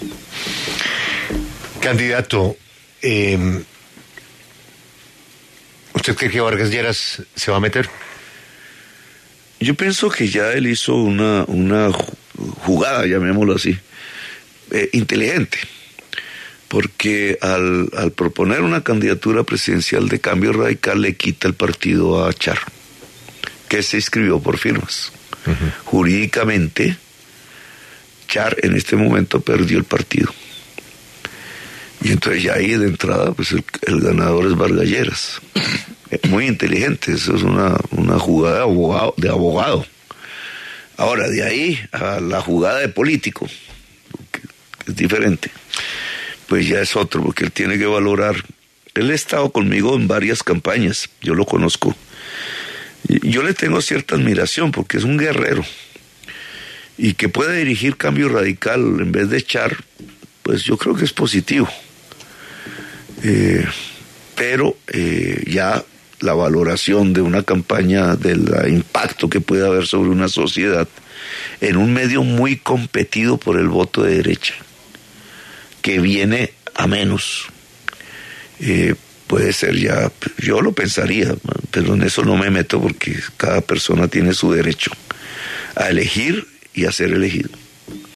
Gustavo Petro, precandidato del Pacto Histórico, habla en La W